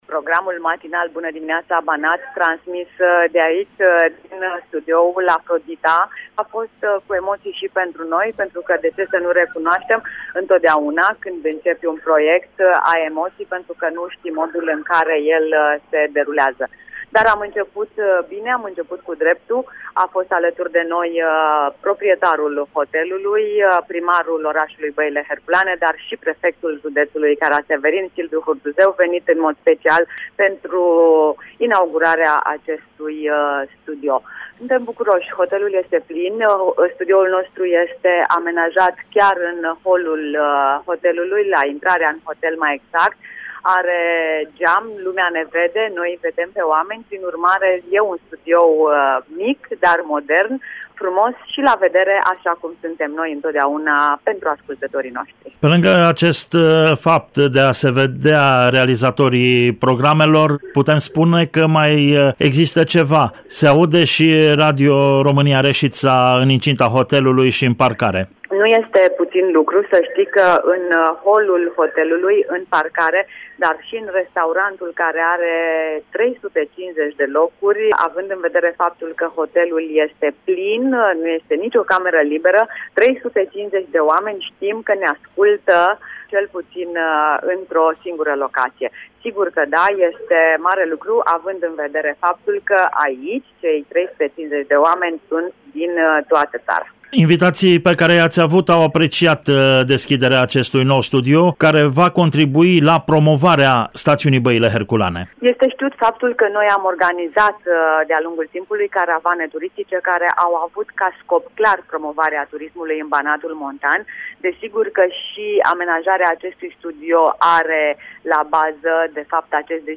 Programul Bună Dimineaţa Banat al postului nostru de radio a fost realizat sâmbătă, 7 martie, şi va fi şi duminică, 8 martie, din cel mai tânăr studio al Radio România Reşiţa, amplasat în holul hotelului Afrodita din Băile Herculene.